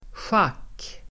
Uttal: [sjak:]